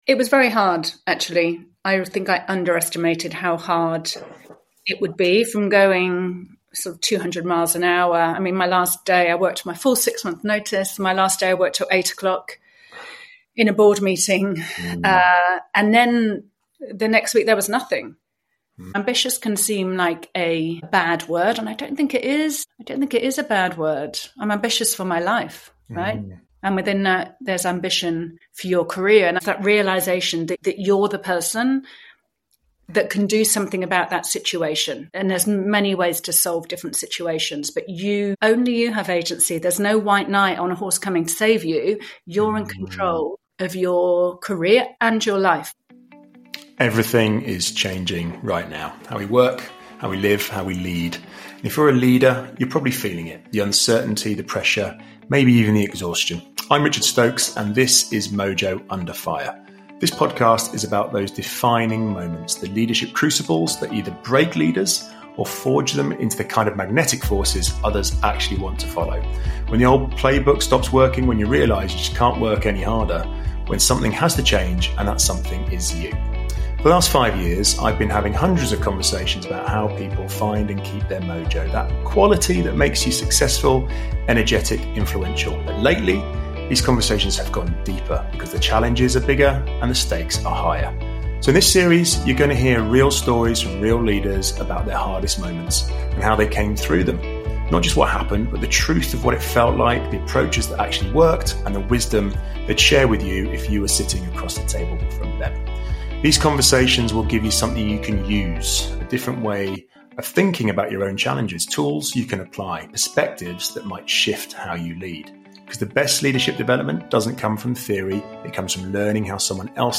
The conversation we had about this decision is one of the most honest I've recorded.